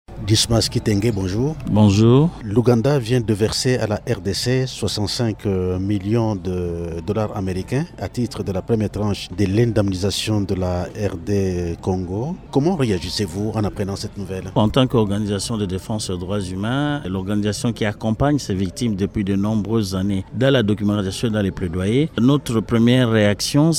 Le Groupe Lotus plaide, à travers une interview accordée lundi 12 septembre à Radio Okapi, pour l’identification des victimes des atrocités commises par l’Ouganda en RDC, mais aussi l’identification de leurs besoins.